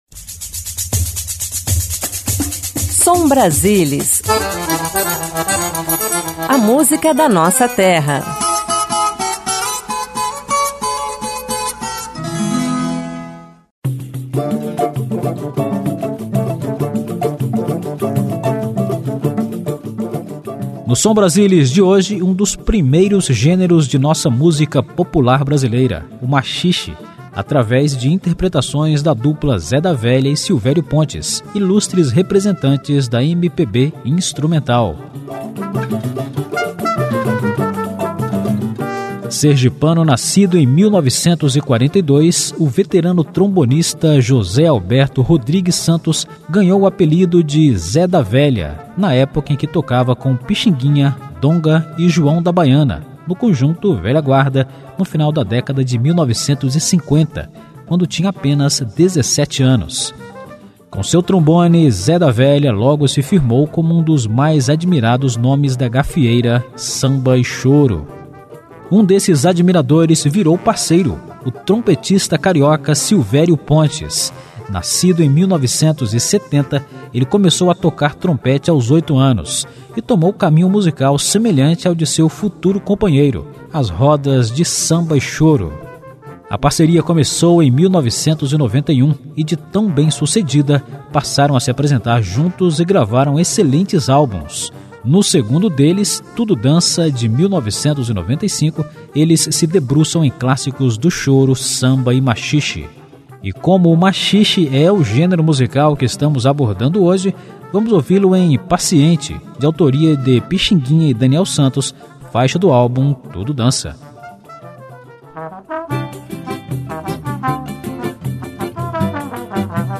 Música instrumental
Gafieira